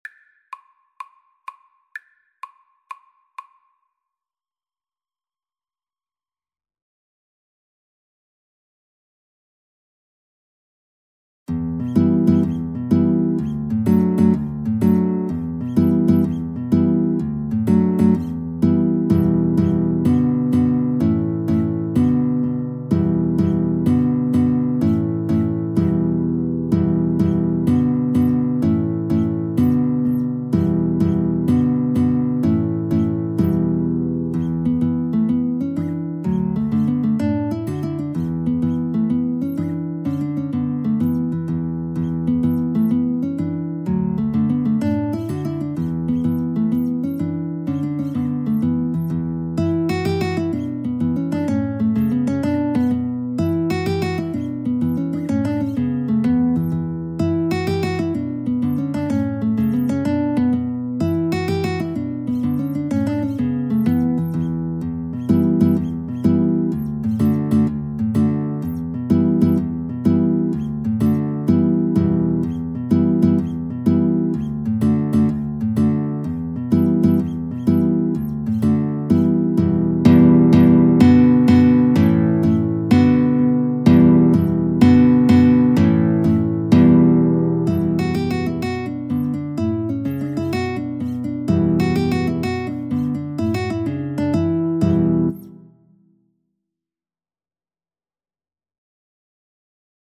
Free Sheet music for Violin-Guitar Duet
D major (Sounding Pitch) (View more D major Music for Violin-Guitar Duet )
Fast .=c.126
12/8 (View more 12/8 Music)
Irish